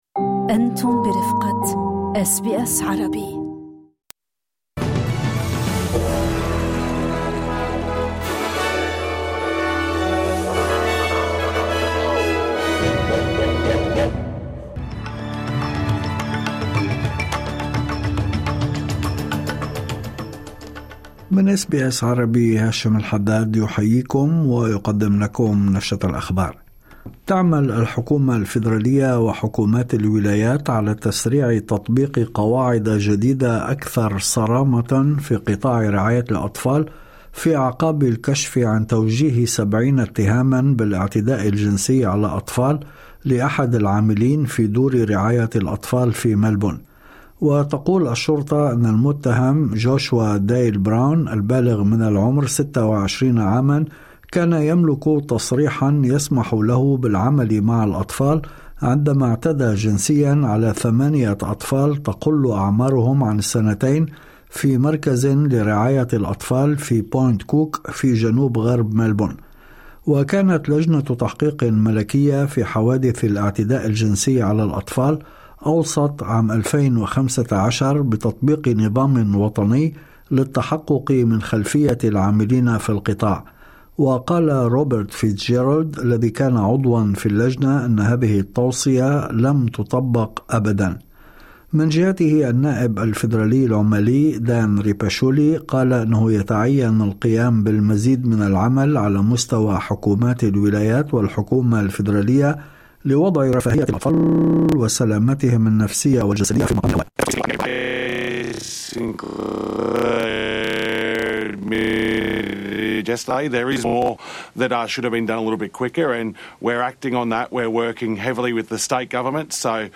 نشرة أخبار الظهيرة 4/7/2025